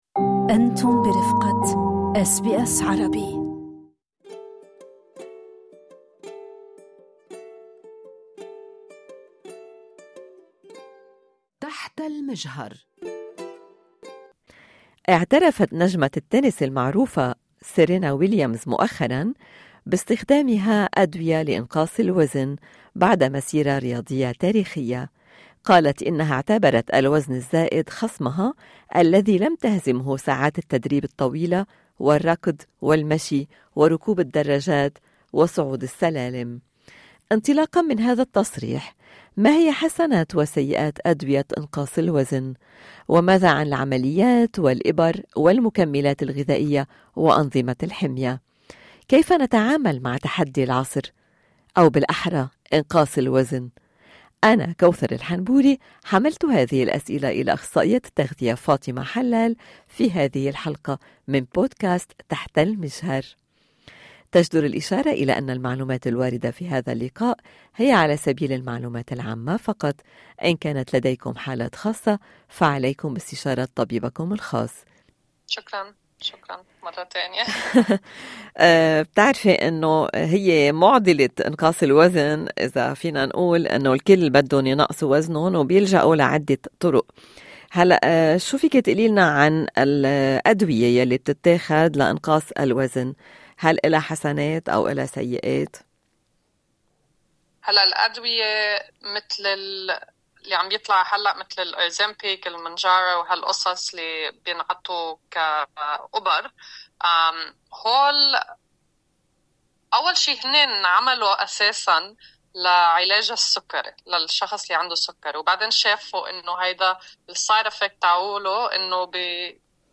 المقابلة